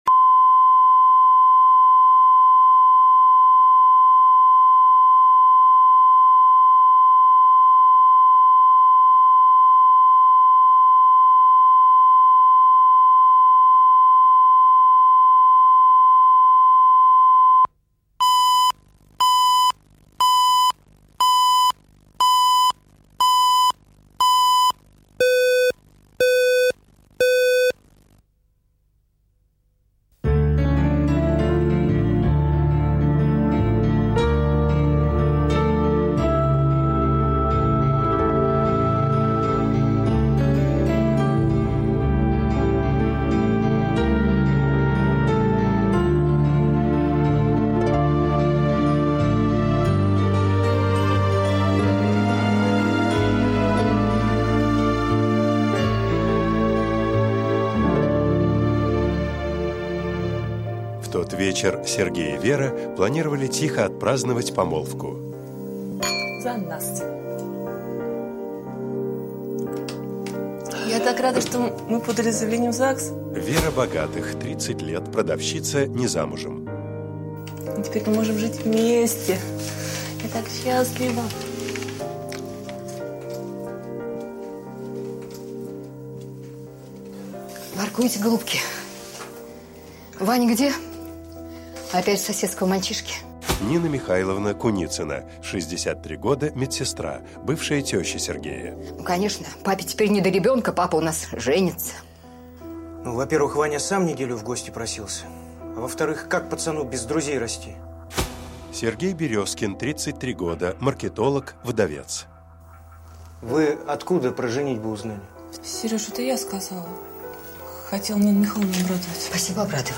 Аудиокнига Мама для Вани | Библиотека аудиокниг